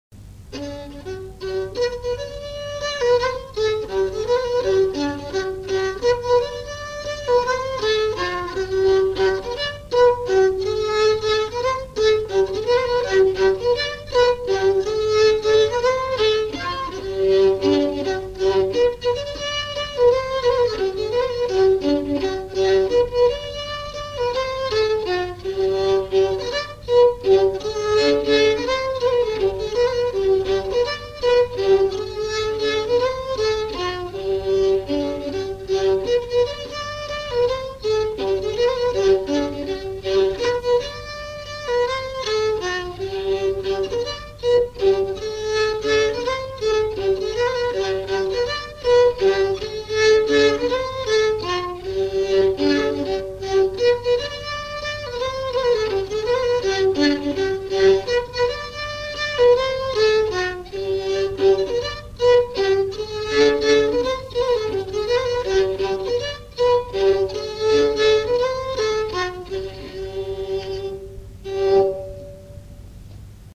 Mémoires et Patrimoines vivants - RaddO est une base de données d'archives iconographiques et sonores.
danse : scottich trois pas
enregistrements du Répertoire du violoneux
Pièce musicale inédite